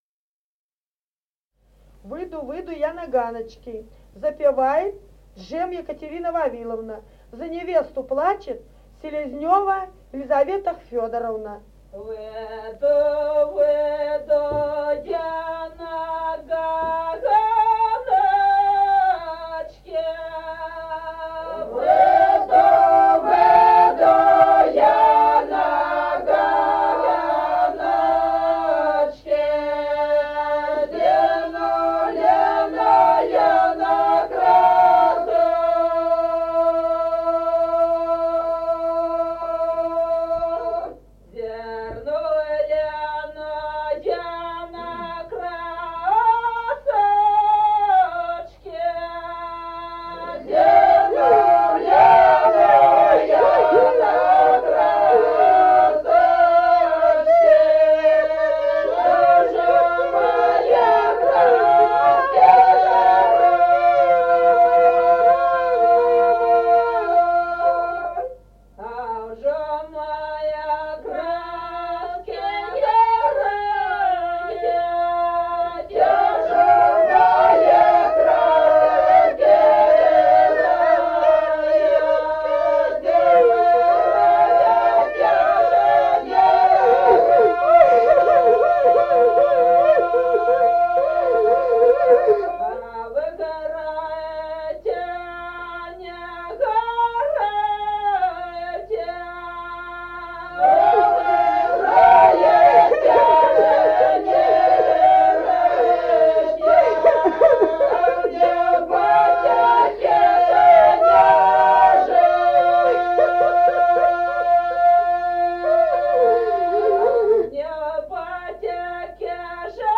Файл:098а Выйду, выйду я на ганочки (с плачем) И0070-03 Остроглядово.wav.mp3 — Фолк депозитарий
с. Остроглядово.